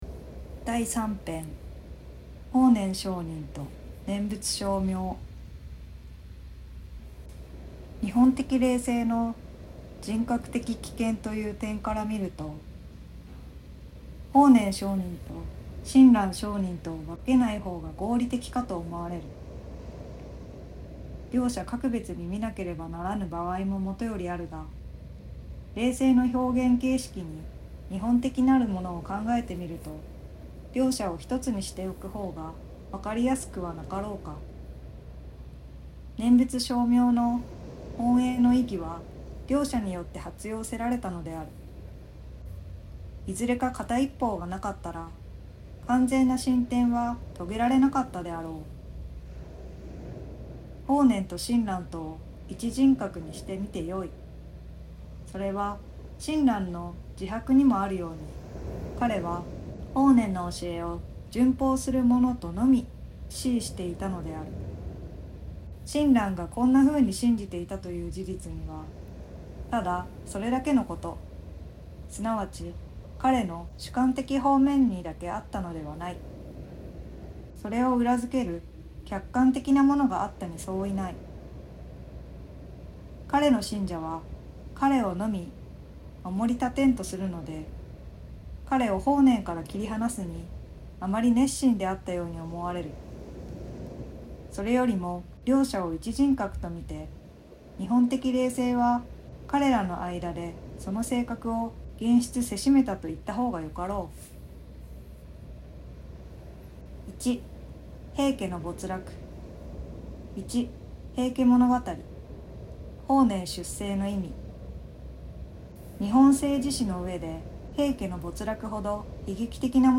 心を豊かにする朗読。